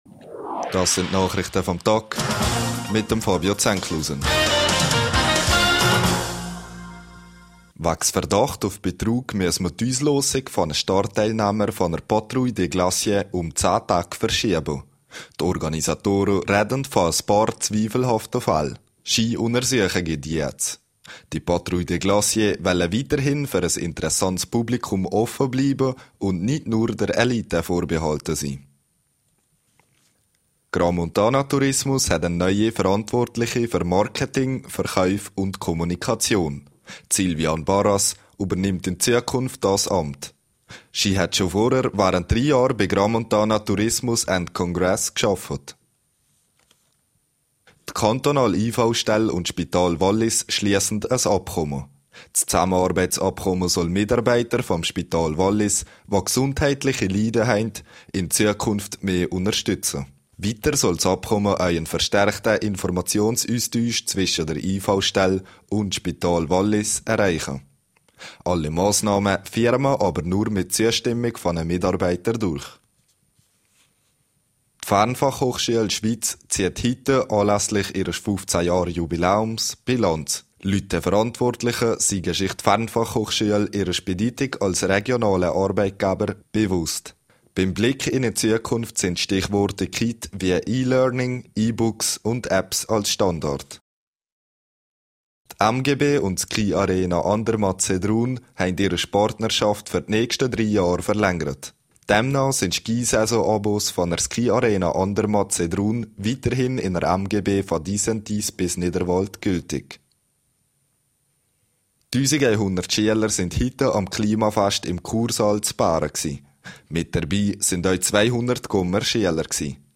Nachrichte vam Tag (4.46MB)